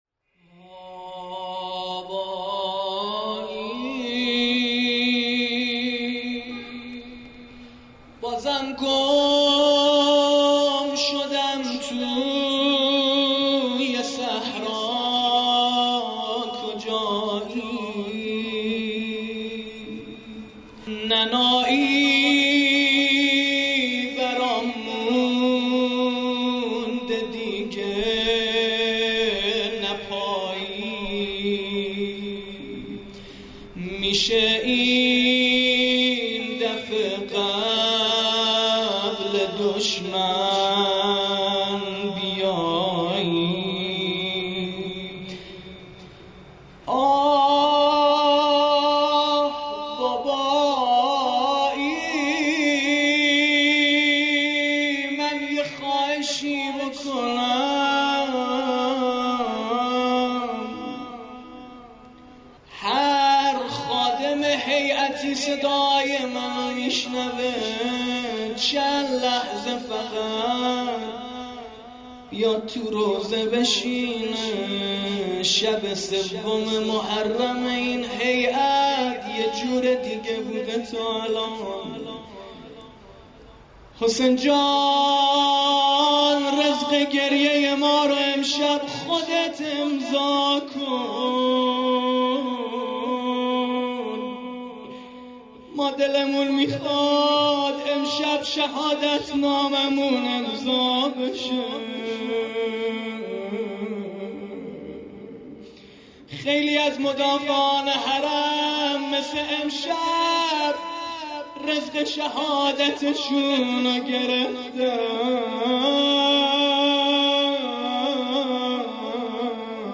◼عزاداری دهه اول محرم - ۱۳۹۹/۶/۱